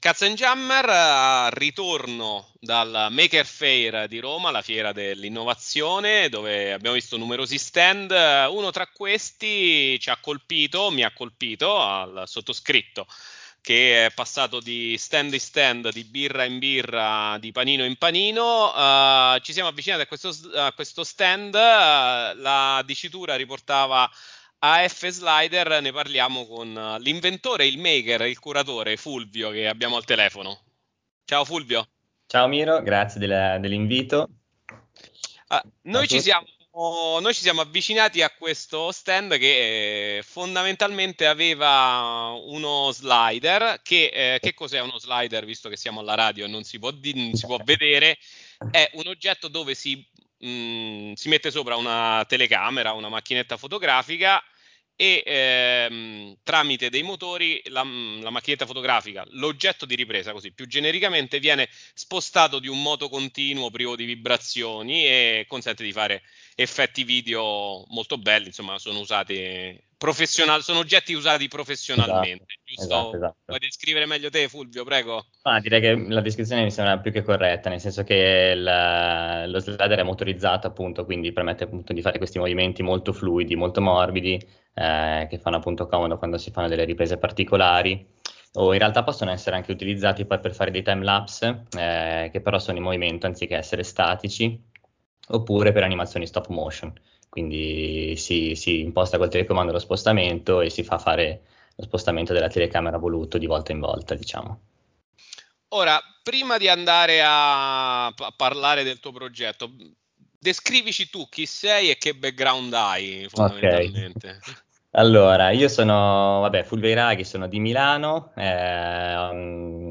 Intervista per il progetto AFSlider | Radio Città Aperta